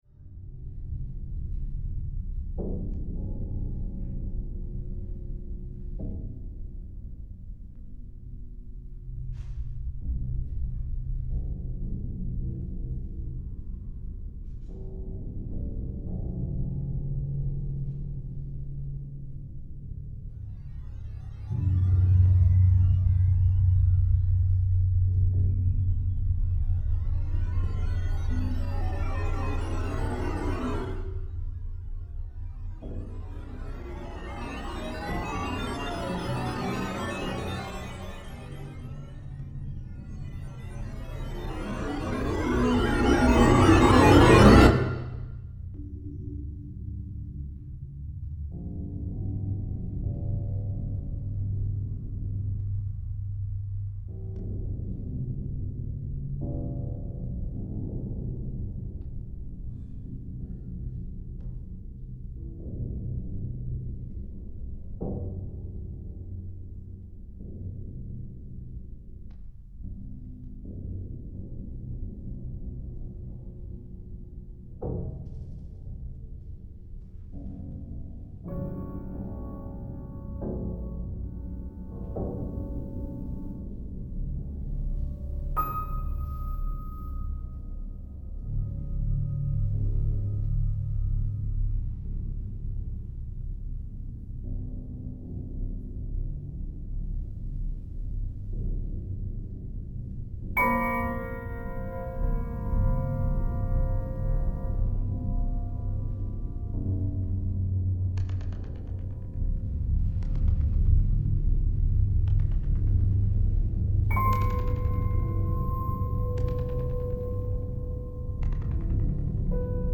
Klavier, Computer
Gleichzeit ist es möglich, in die Abfolge einzugreifen; durch digitale Effekte kommt es zu teils extremen Klangverfremdungen. So entsteht während der Aufführung eine Vielzahl von Klängen und Klangverbindungen / Klangschichtungen, die sich mit den improvisierten Live-Klängen des Klaviers mischt.